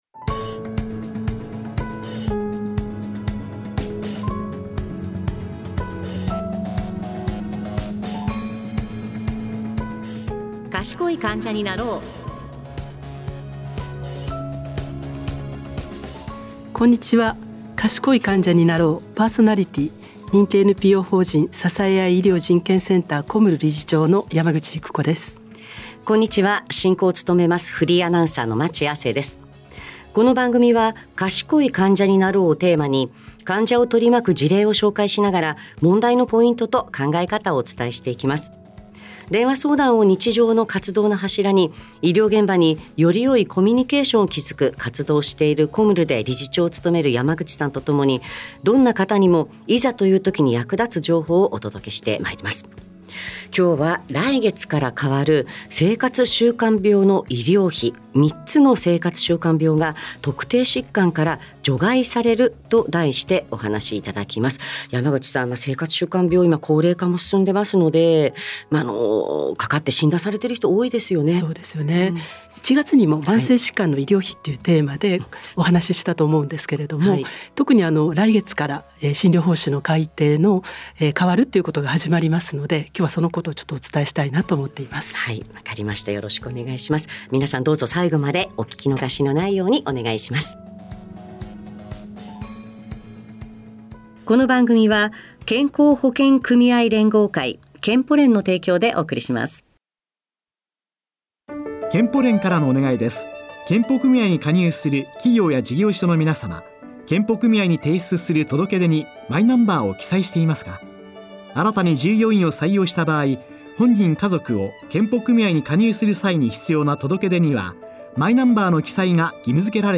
進行：フリーアナウンサー